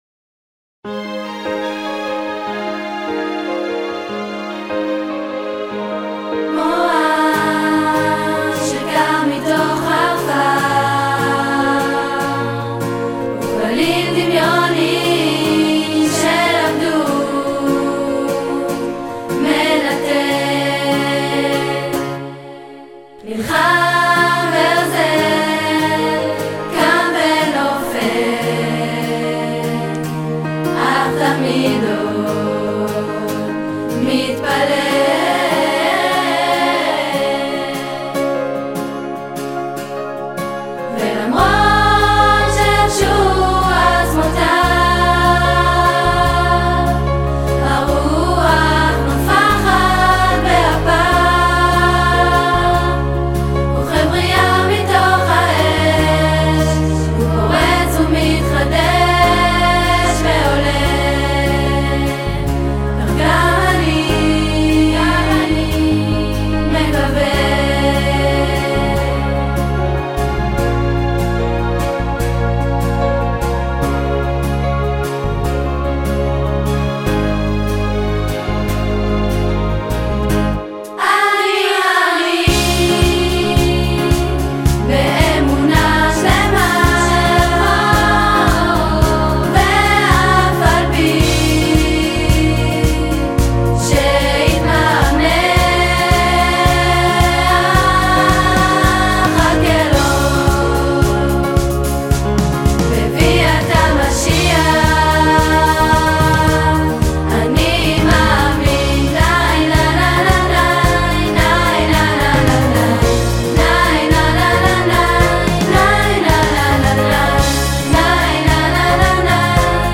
את הביצוע המצורף מבצעות 13 בנות ביחד, לכל מי שלא שומע- שישים לב!
אולי היה כדאי להוסיף גם כלים חיים חוץ מאורגנית..
במילה "נופל" יש ירידה באוקטבה.
במילה "מתפלל" שאמורה לבטא תפילה, יש את הסלסולים ממש כמו בתפילה, בתחינה.[ולכן גם המילה לבד ,ירדה בפסיחה]
בקשר לכלים- יש גם תופים וחוצמזה שזה פלייבק מקצועי.
הקלטנו את עצמנו בלי מוזיקה בכלל ושלחנו למישהו שיעשה לנו פלייבק והוא שלח לנו את זה חזרה במייל. התאמנו על זה ואז נסענו לאולפן להקליט את השיר...